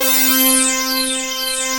BIG LEADC4-R.wav